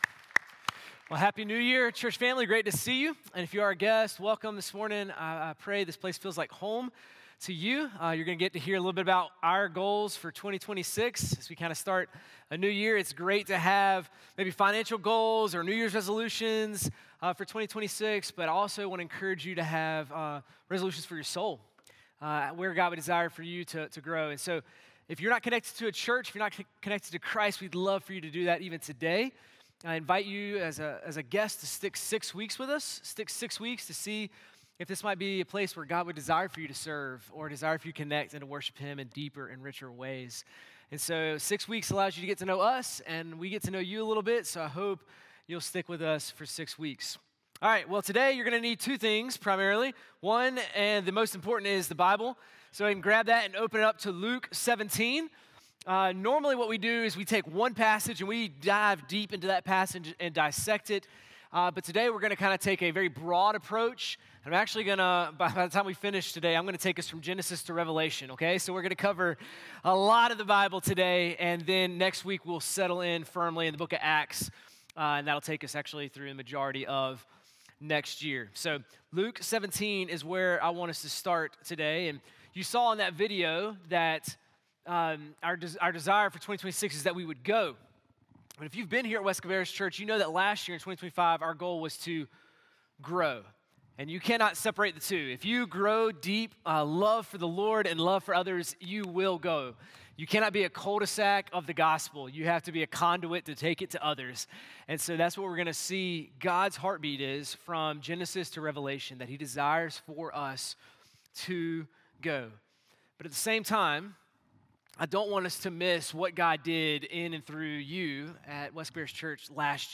sermon-1-4-26.mp3